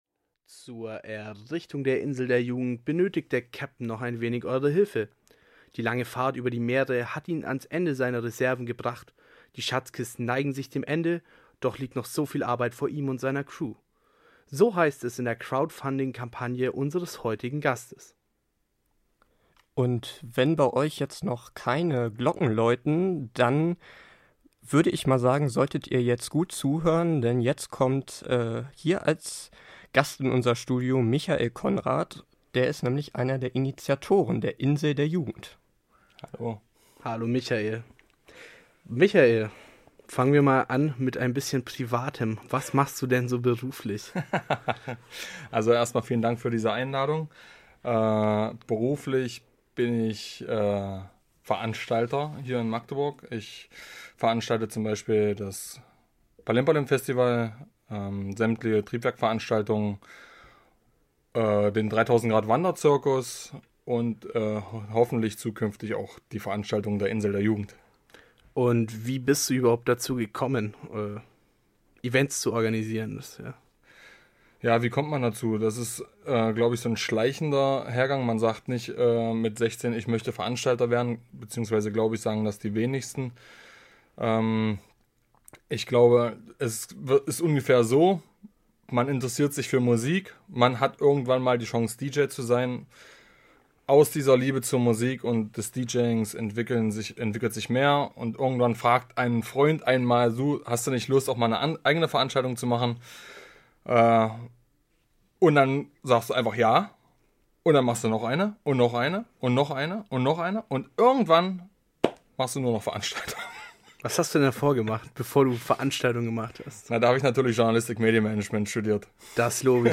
im Gespräch – Die Insel der Jugend